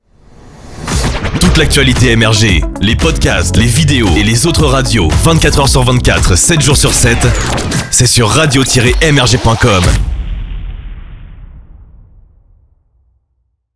medleys (mini-mix)
• Réalisés par nos DJ producteurs